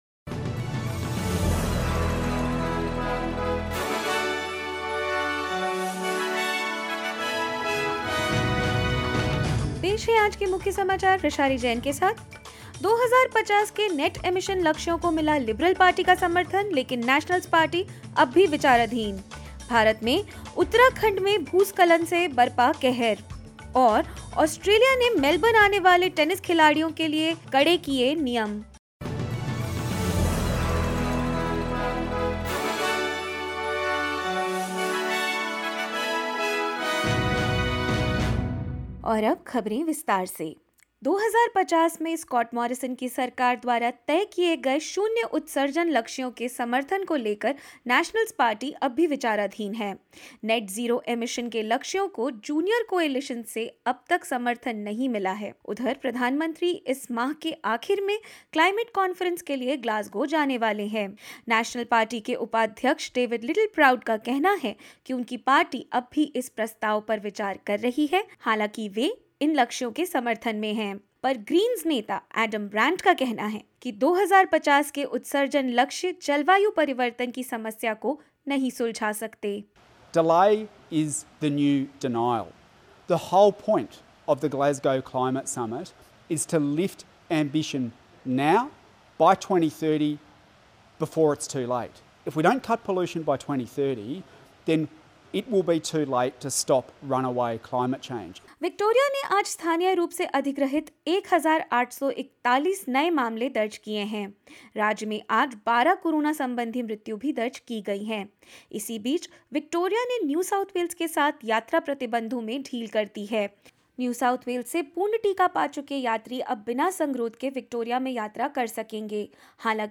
In this latest SBS Hindi News bulletin of Australia and India: Nationals remain undecided over net zero emissions as Prime Minister Morrison prepares for Glasgow Climate Conference; Victoria opens borders with NSW, but certain restrictions apply and more.